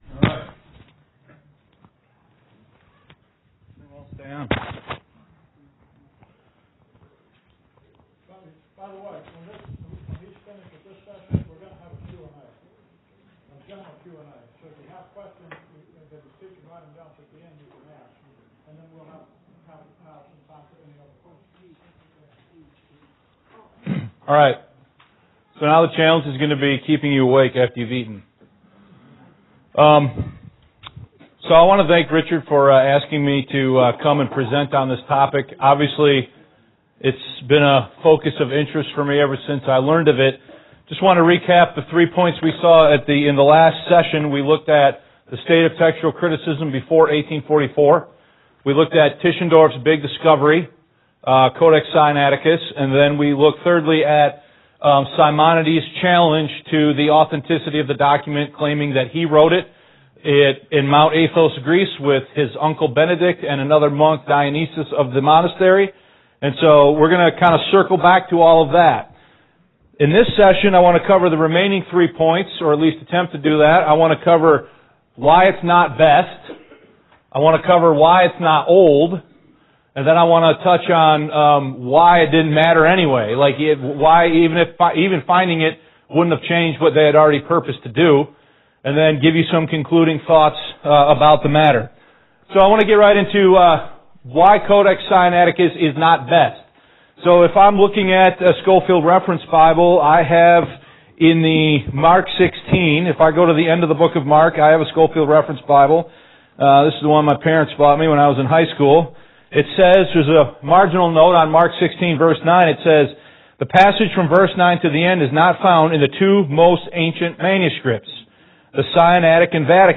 This was a two-part study taught at Shorewood Bible Church in Rolling Meadow, IL on April 28, 2018.